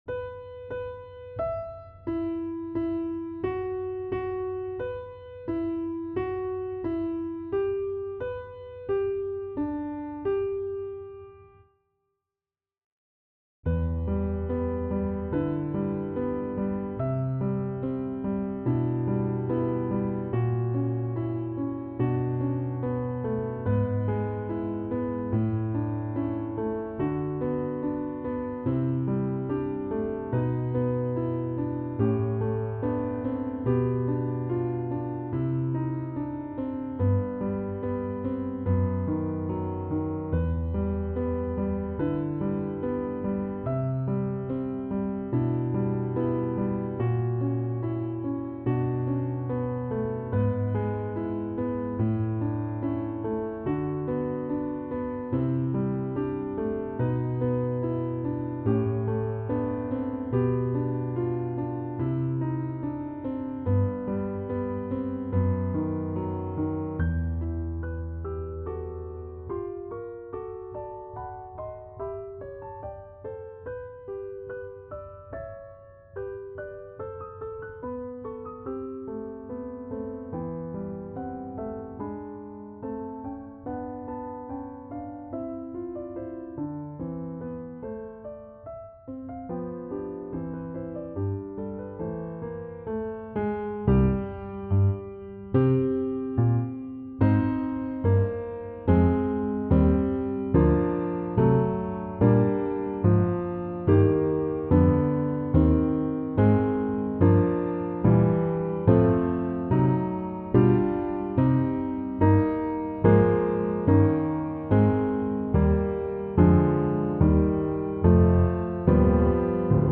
Piano (2025)